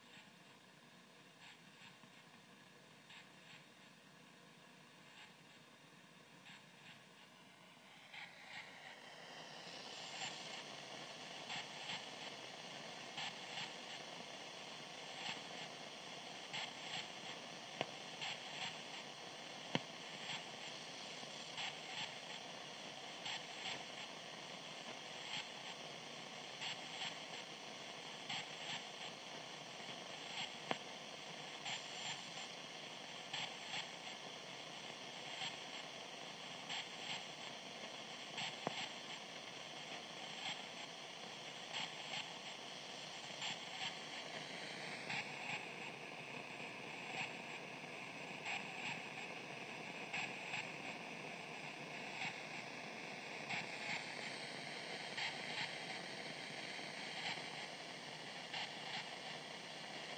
LW 144kHz - what is this? Presumably I've not gone and discovered a communication from Vega ;)